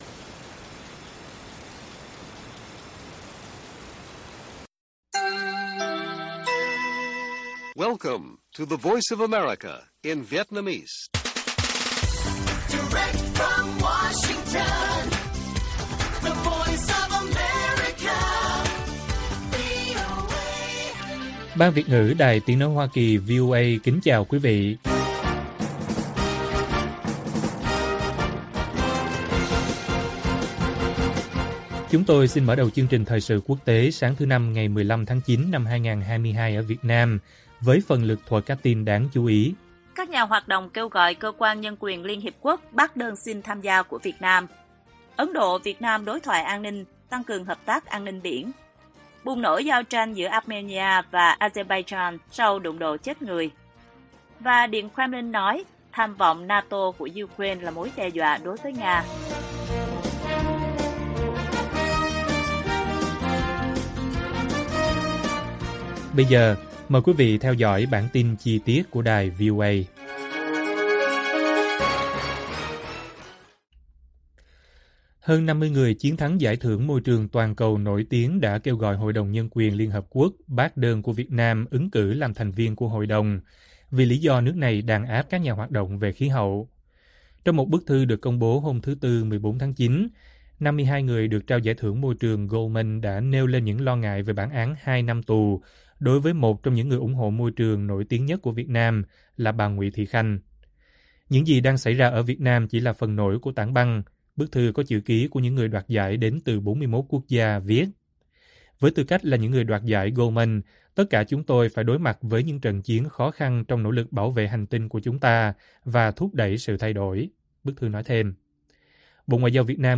Bùng nổ giao tranh giữa Armenia và Azerbaijan sau đụng độ chết người - Bản tin VOA